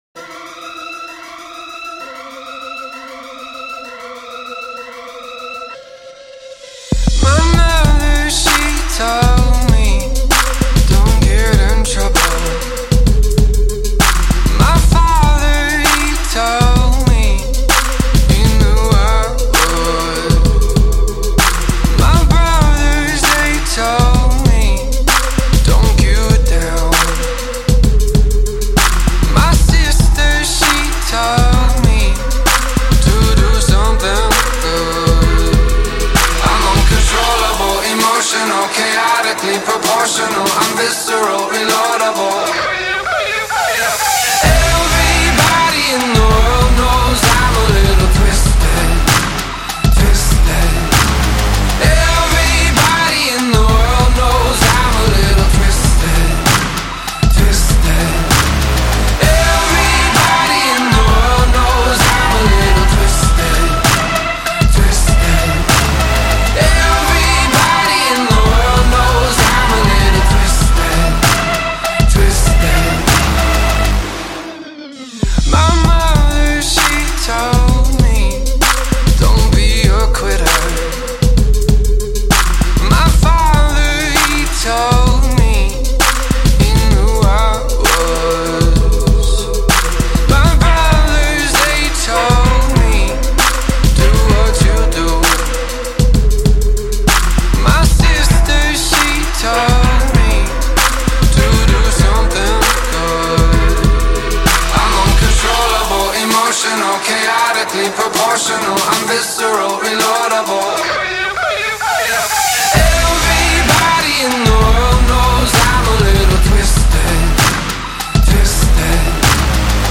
آلترناتیو ایندی